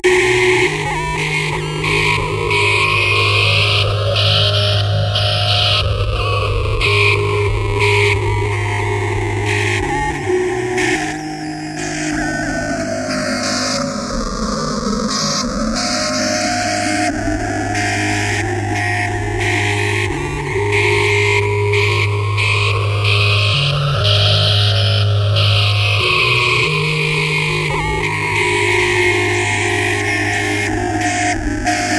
标签： 120 bpm Industrial Loops Bass Guitar Loops 5.38 MB wav Key : Unknown
声道立体声